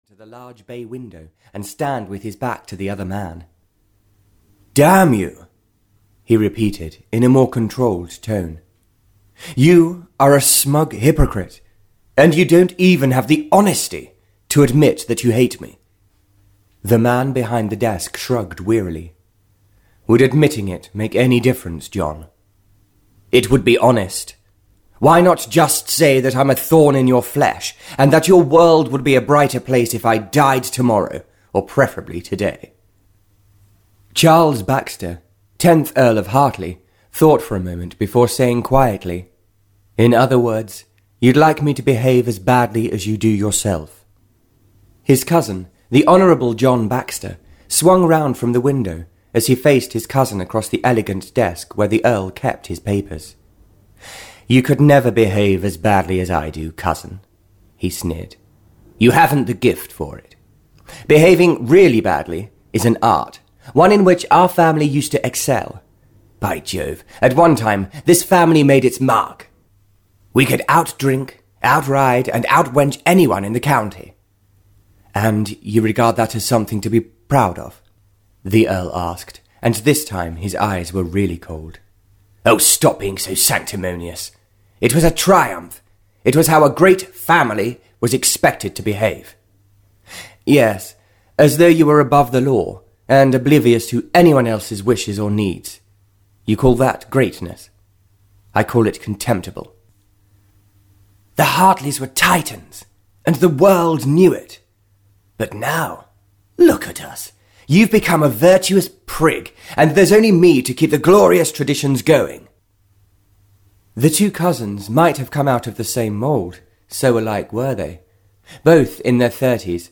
Audio knihaThe Star of Love (Barbara Cartland's Pink Collection 12) (EN)
Ukázka z knihy